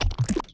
drop.wav